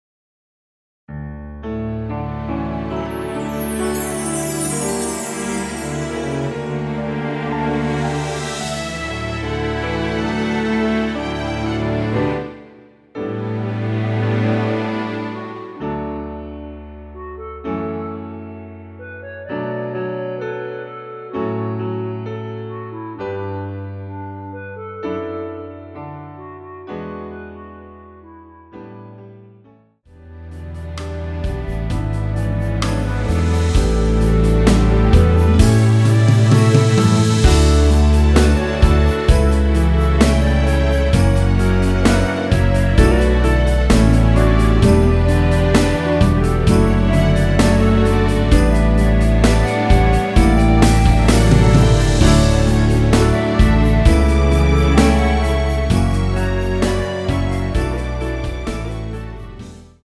원키에서(-2)내린 멜로디 포함된 MR입니다.(미리듣기 확인)
멜로디 MR이라고 합니다.
앞부분30초, 뒷부분30초씩 편집해서 올려 드리고 있습니다.
중간에 음이 끈어지고 다시 나오는 이유는